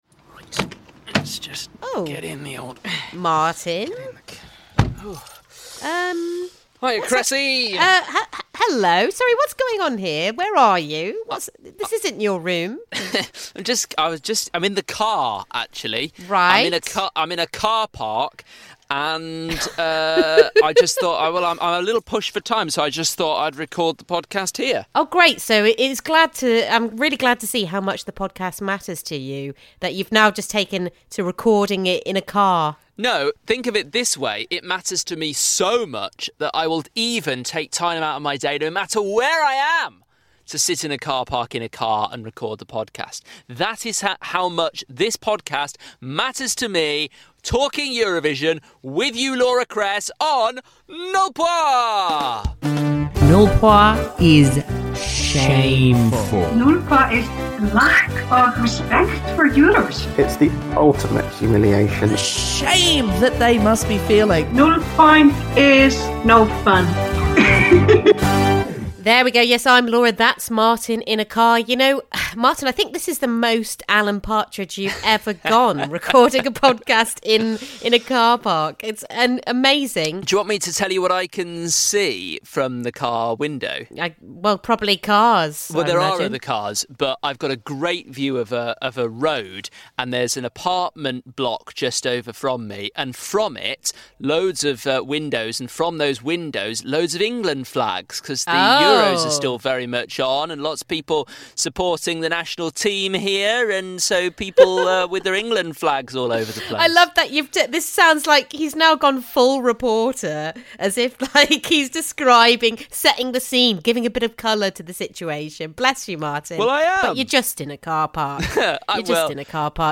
Two UK mates chat about the latest news from the world of Eurovision.